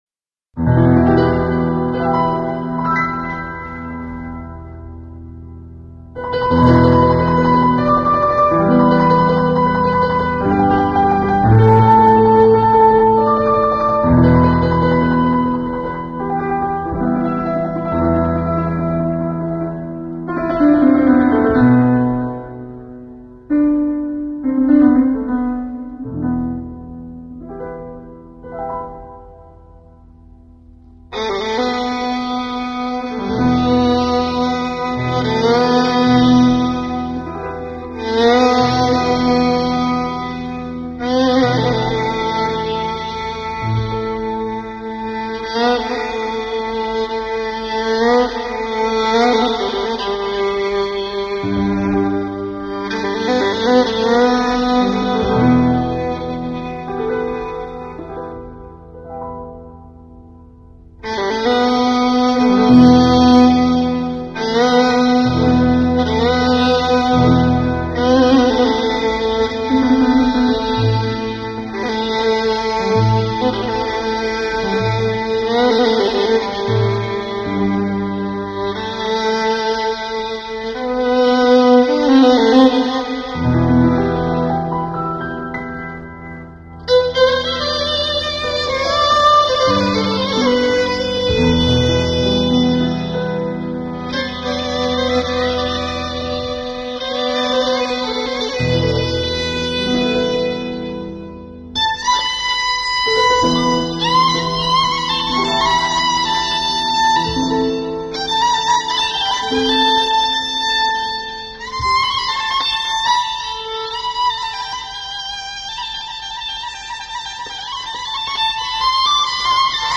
پیانو
ویولن
ضرب
مایه دشتی
موسیقی اصیل ایرانی